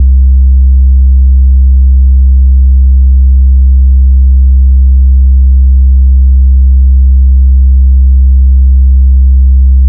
DDWV BASS 1.wav